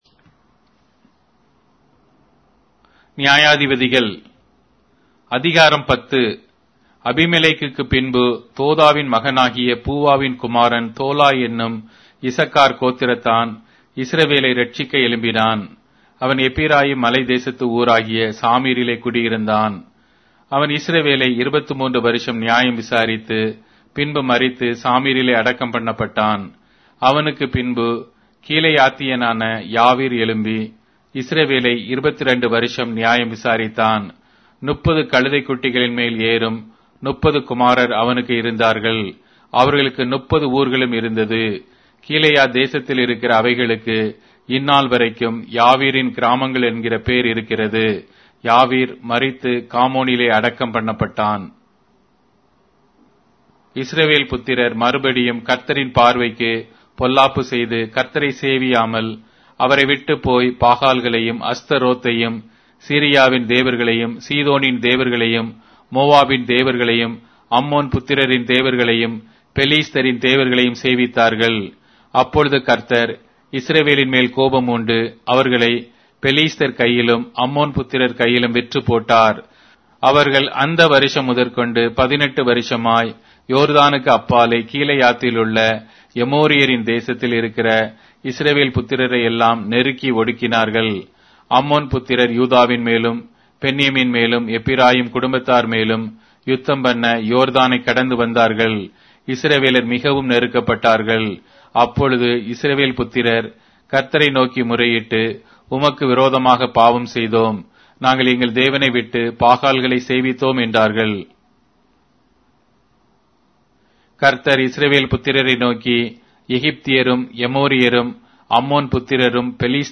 Tamil Audio Bible - Judges 10 in Ylt bible version